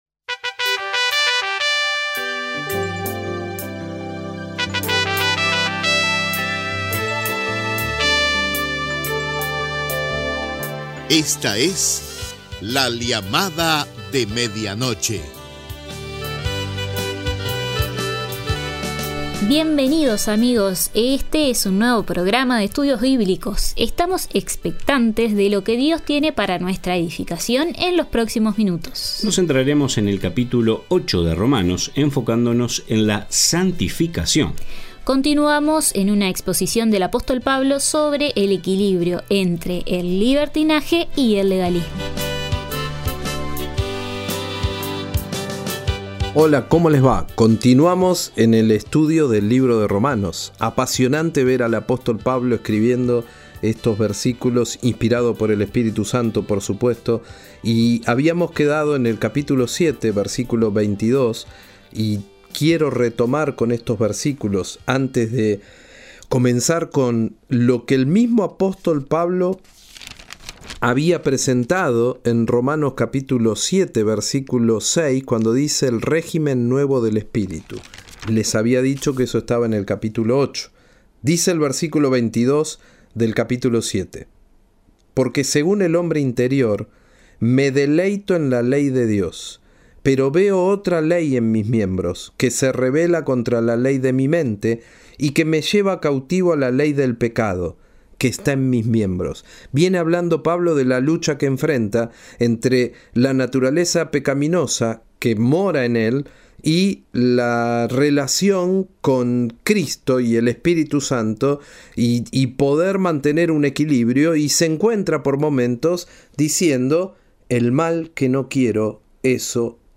Próximamente, transcripción de la entrevista